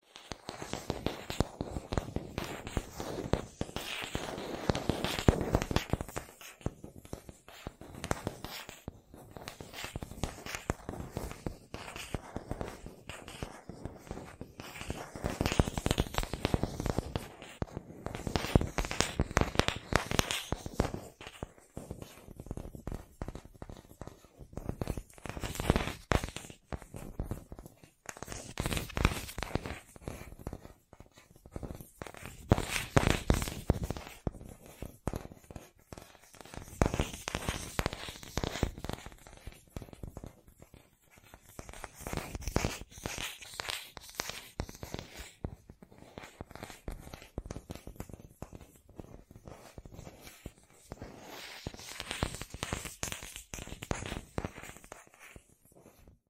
Девушка постукивает ногтями по деревянной подставке